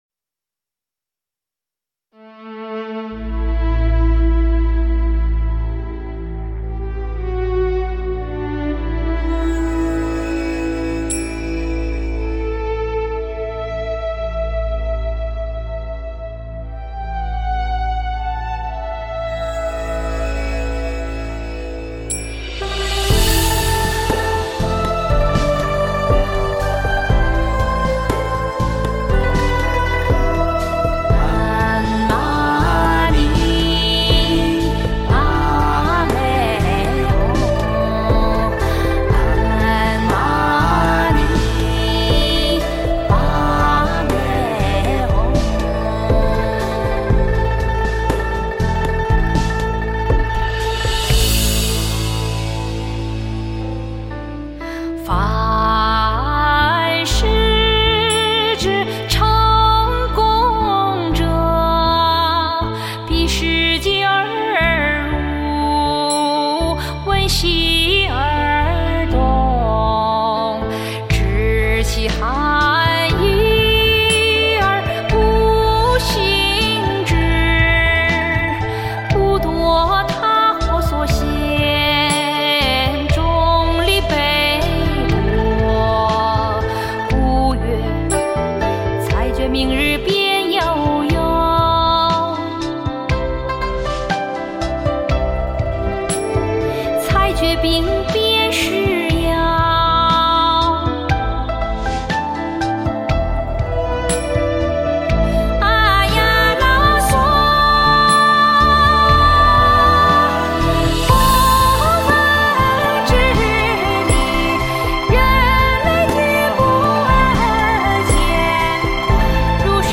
【舒缓悠扬的曲调，如行云流水，如策马在草原，宁静、致远】